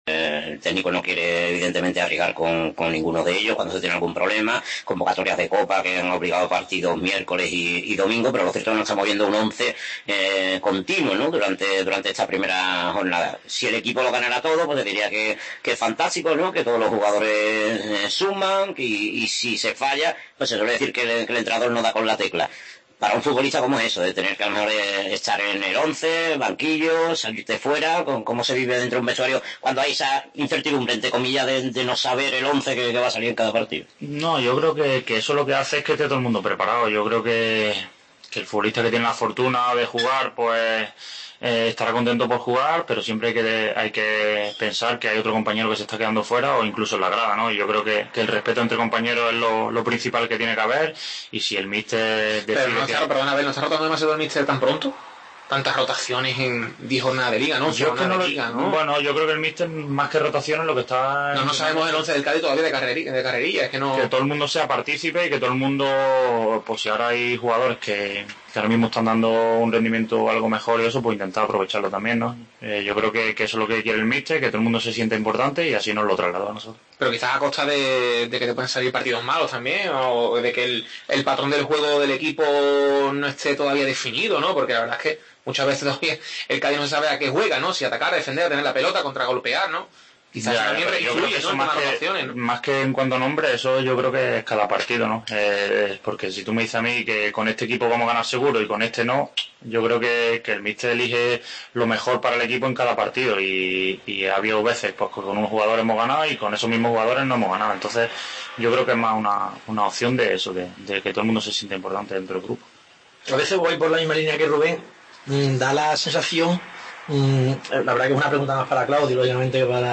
Segunda parte de la tertulia desde De Otero